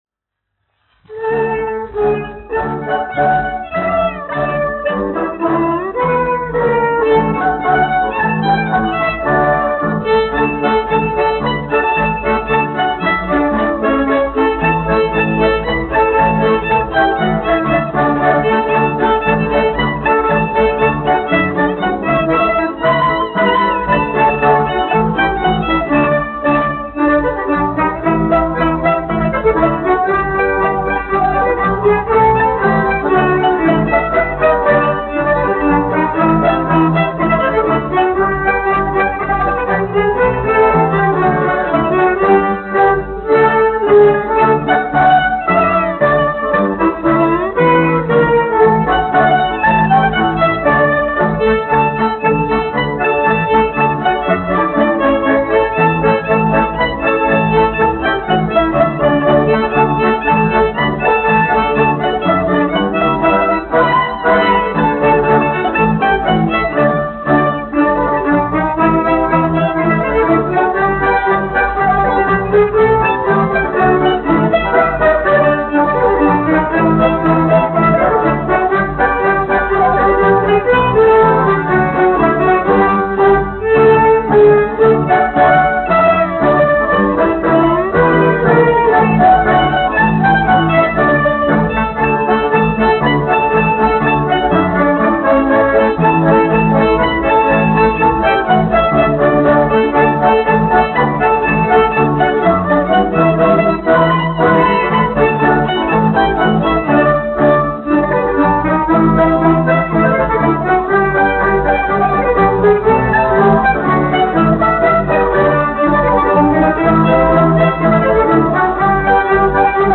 Zaglītis : tautas deja
1 skpl. : analogs, 78 apgr/min, mono ; 25 cm
Latviešu tautas dejas